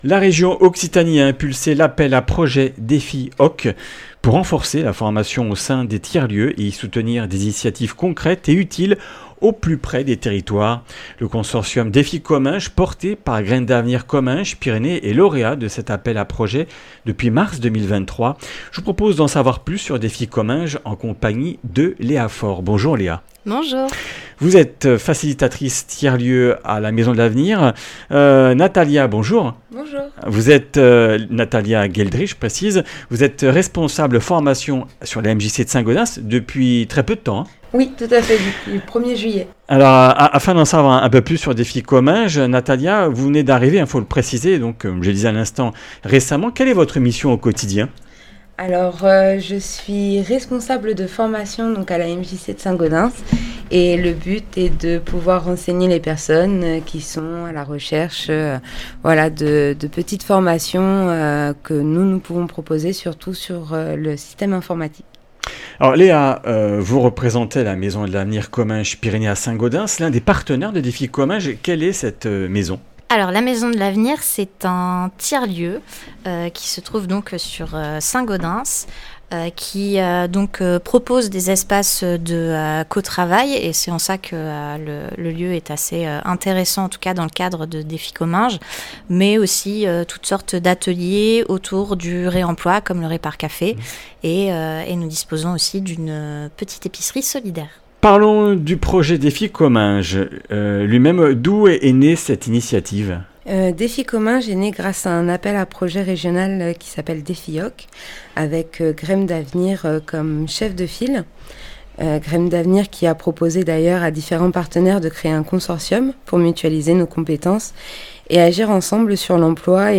Comminges Interviews du 16 juil.
Une émission présentée par